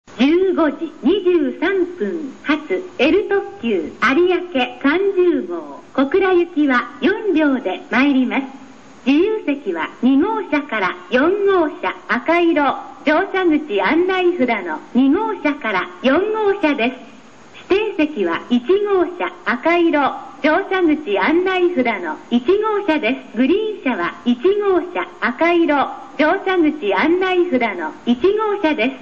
2002年春に収録
スピーカー： カンノ製作所
音質：D
２番のりば 案内放送 Ｌ特急有明・小倉 (141KB/29秒)
他の駅と異なるのは「早口」で「うるさい」です。